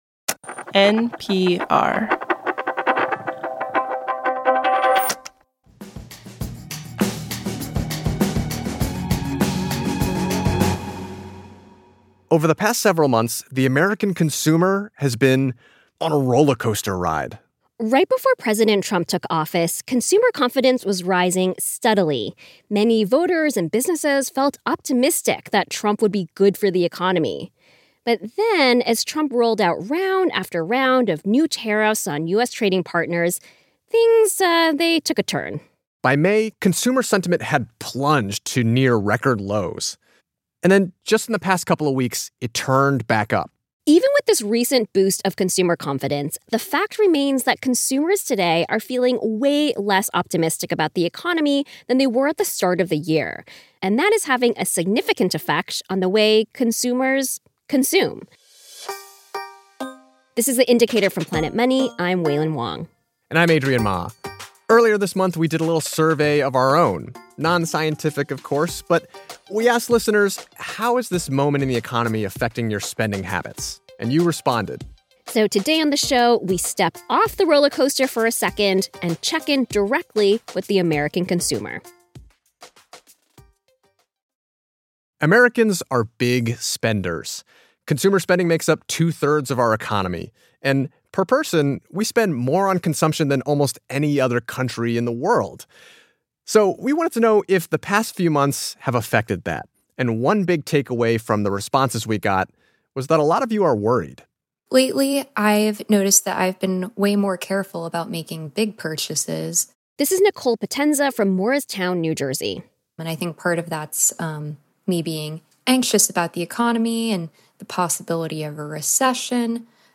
So, we wanted to know how an uncertain economy is affecting that. Today on the show, we hear from consumers directly on how their spending habits have changed the past few months.